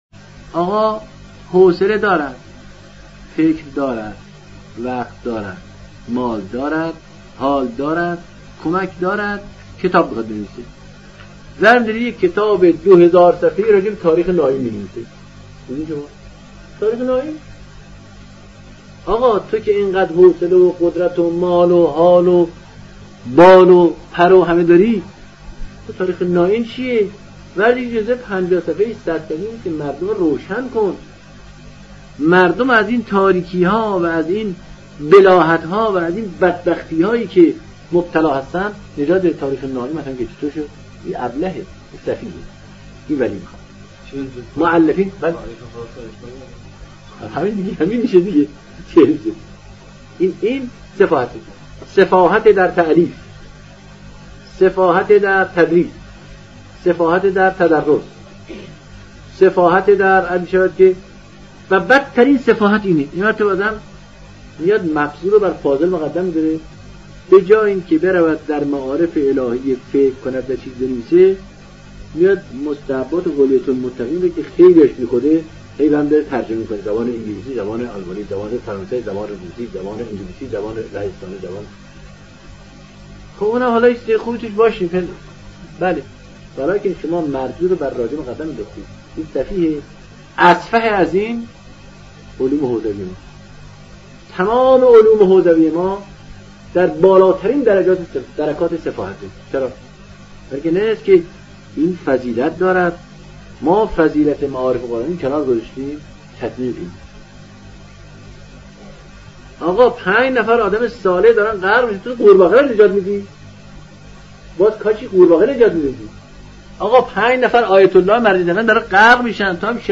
مجموعه صوتی آوای رسالت گزیده ای از برترین سخنان آیت الله العظمی دکتر محمد صادقی تهرانی است که بیشتر گزیده هایی منتخب از تفسیر موضوعی، فقه مقارن، بیوگرافی فقیه قرآنی می باشد.
• نویز(noice) یا همان خش خش و هوای موجود در این گزیده ها تا حد عالی حذف گردیده است .